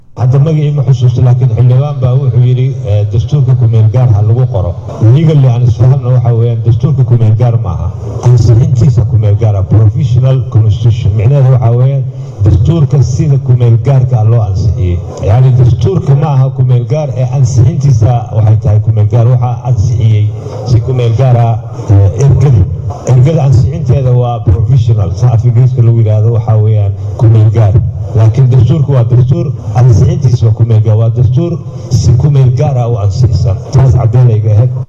Gudoomiyaha Baarlamaanka Soomaaliya Maxamed Sheikh Cismaan Jawaari oo Shalay ka hadlayay Kulankii Baarlamaanka Soomaaliya ayaa sheegay in Dastuurka Soomaaliya uusan xiligaan aheyn KMG islamarkaana Dadka Soomaaliyeed ay wax iskaga qaldan yihiin.